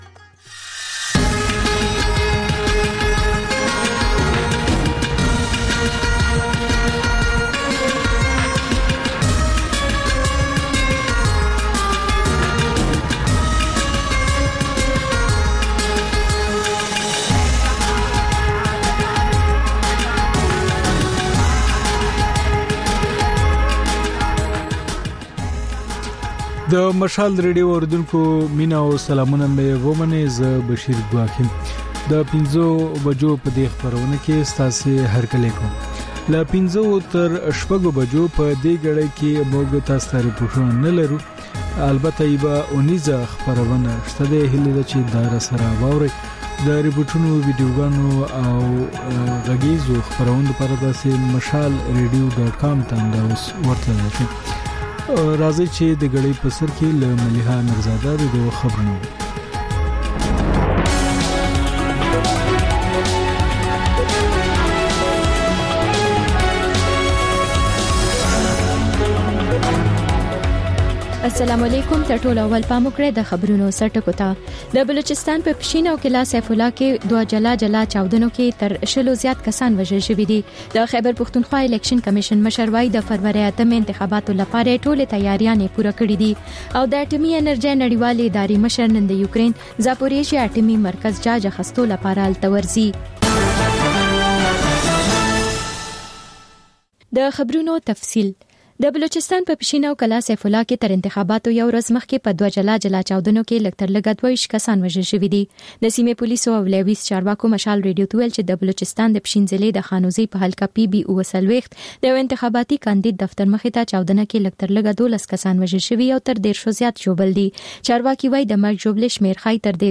د مشال راډیو ماښامنۍ خپرونه. د خپرونې پیل له خبرونو کېږي، بیا ورپسې رپورټونه خپرېږي.
ځېنې ورځې دا ماښامنۍ خپرونه مو یوې ژوندۍ اوونیزې خپرونې ته ځانګړې کړې وي چې تر خبرونو سمدستي وروسته خپرېږي.